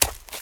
High Quality Footsteps
STEPS Leaves, Run 05.wav